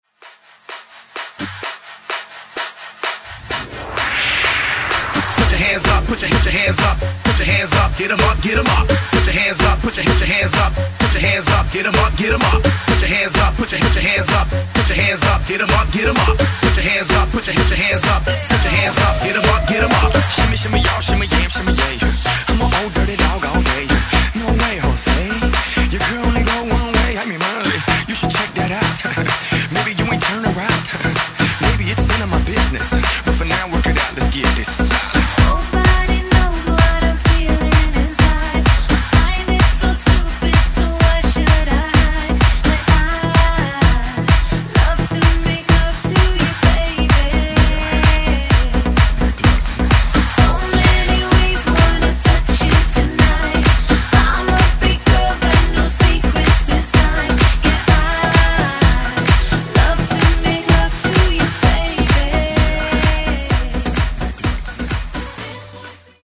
128 Bpm Genre